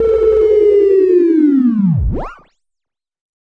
描述：与"Attack Zound01"相似，但有一个长的衰减，在衰减结束时有一个奇怪的声音效果。这个声音是用Cubase SX中的Waldorf Attack VSTi制作的。
Tag: 电子 SoundEffect中